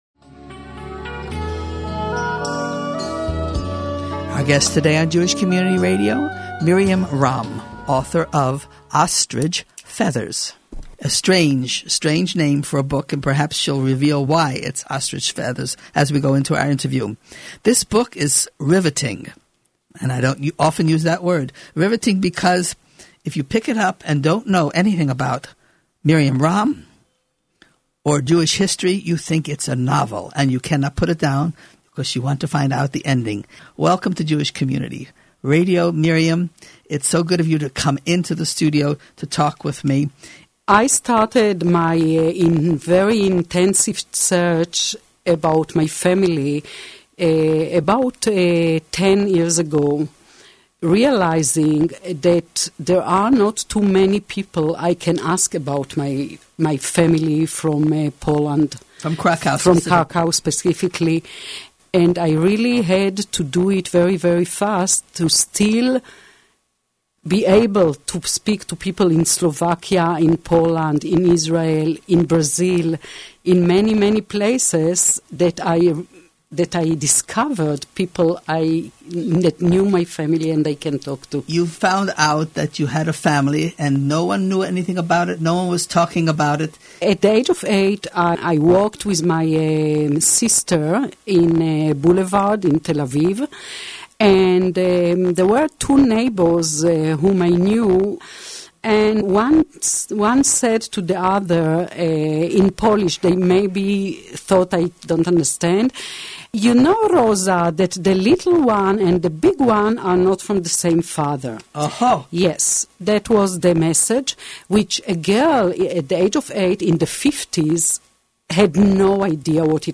Interview - Jewish Community Radio